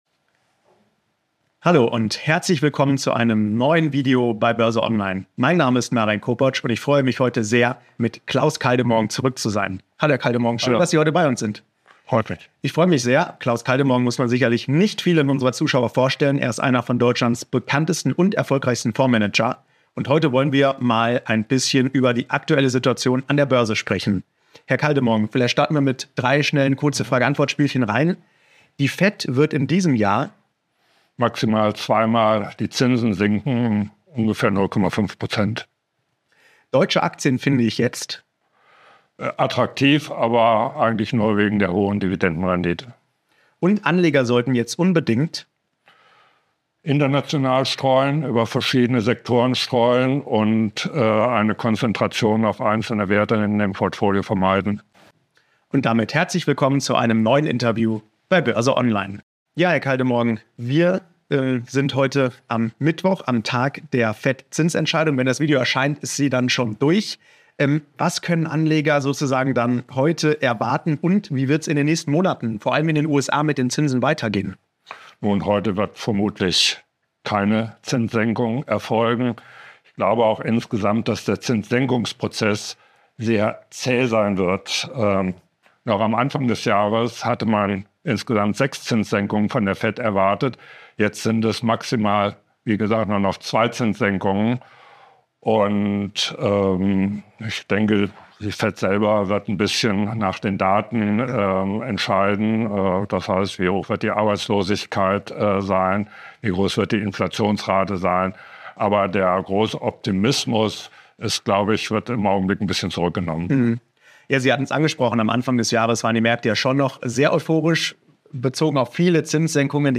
Wie er jetzt ein Depot aufbauen würde, welche Rolle Gold und Bitcoin darin spielen, wie Anleger mit Anleihen umgehen sollten und ab wann es an der Börse ungemütlich werden könnte, das verrät Klaus Kaldemorgen im Interview mit BÖRSE ONLINE. Auf welche Aktien der Fondsmanager jetzt setzt, warum jeder in KI investieren sollte und was die wichtigsten Tipps des Fondsmanagers aus über 40 Jahren Börsen-Erfahrung sind.